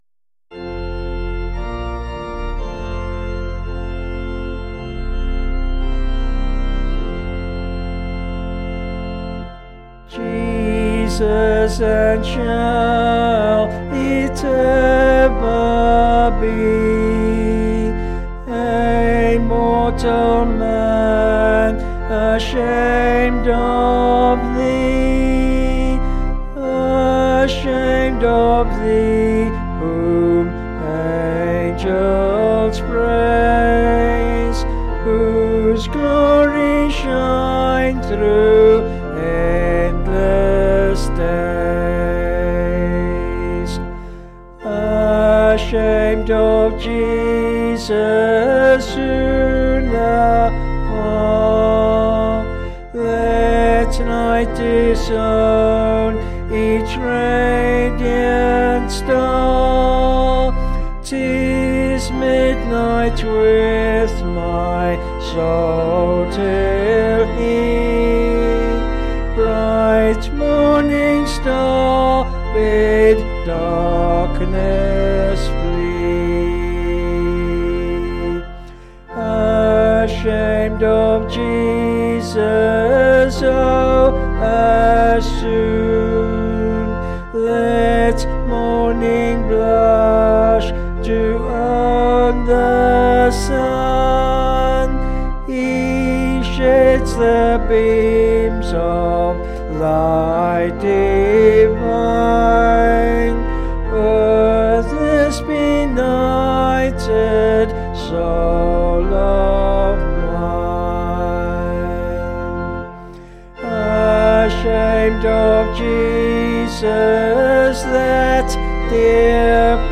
Vocals and Organ   264.4kb Sung Lyrics